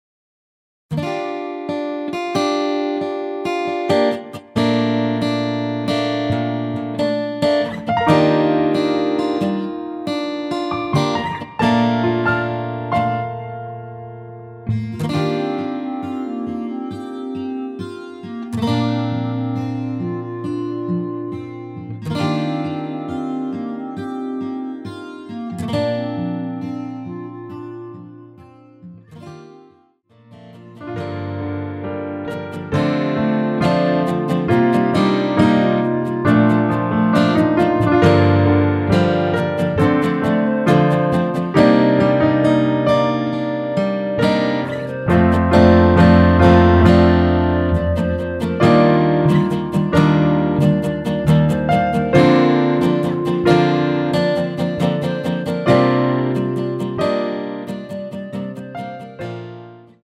원키에서(-1)내린 멜로디 포함된 MR입니다.
Bb
앞부분30초, 뒷부분30초씩 편집해서 올려 드리고 있습니다.
중간에 음이 끈어지고 다시 나오는 이유는